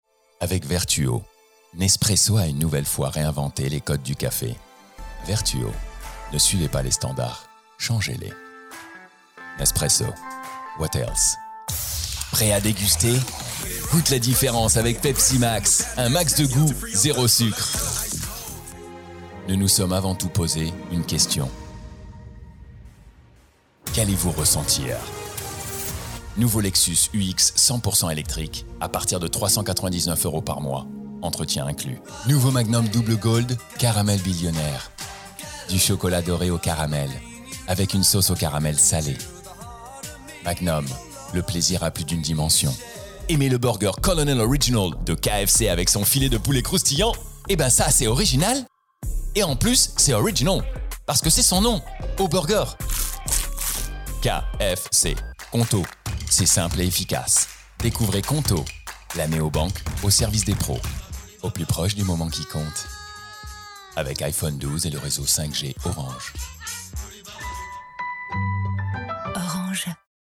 Voix off
Narrateur
20 - 60 ans - Basse Baryton-basse Baryton